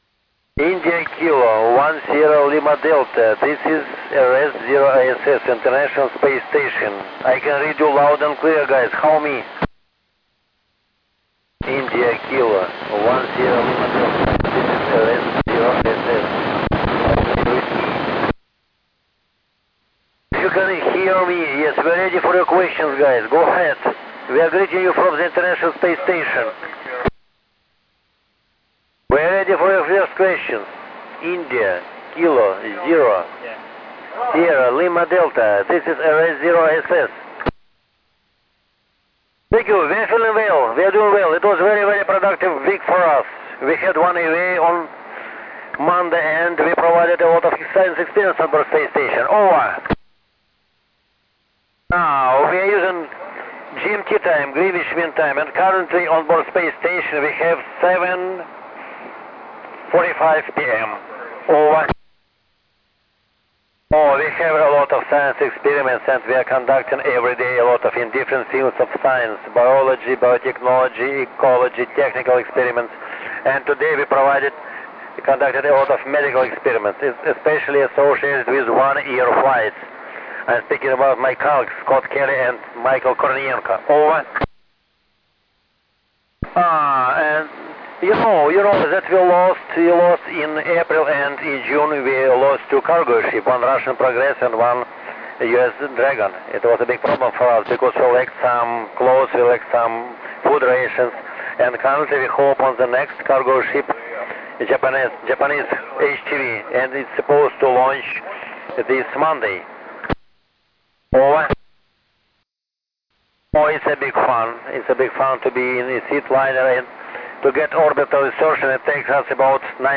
School contact with Festival d'astronomie de Fleurance, France on 14. August 2015 at 19:43 UTC
With the cosmonauts Gennary Padalka, RN3DT.